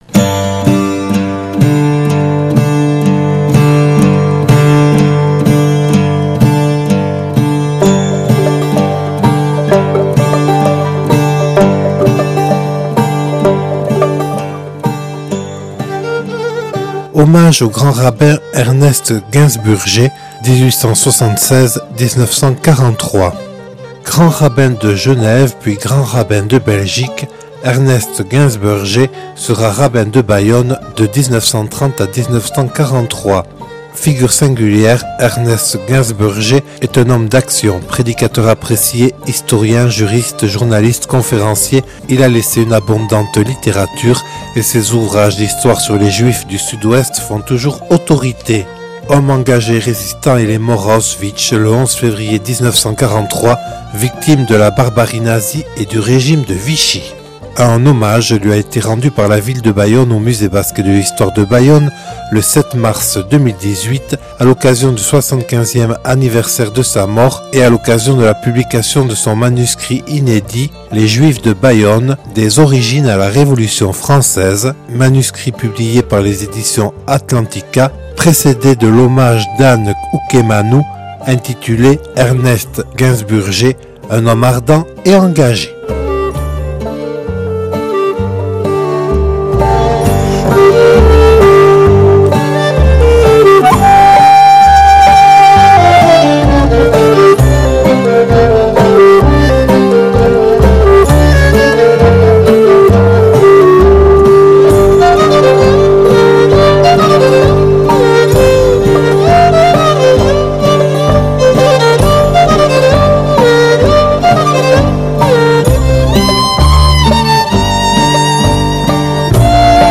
(Enregistré le 07/03/2018 au Musée Basque et de l’Histoire de Bayonne).